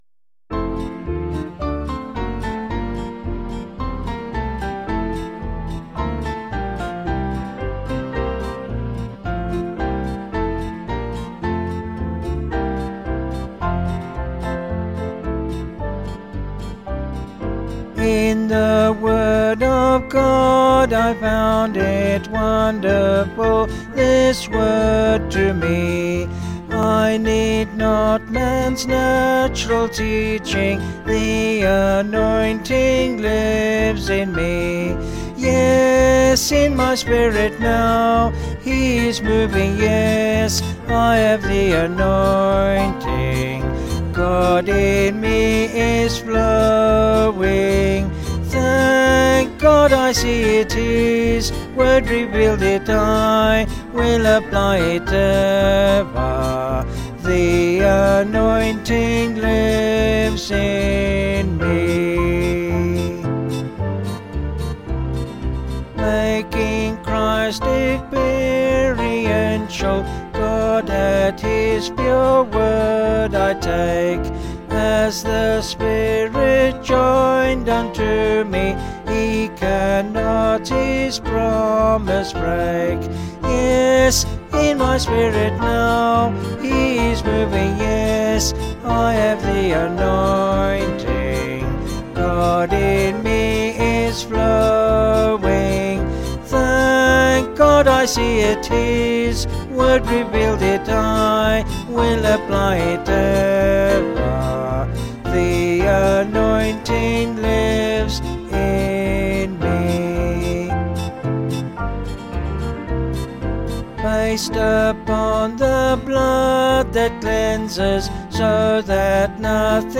(BH)   6/F-Gb
Vocals and Band   266.6kb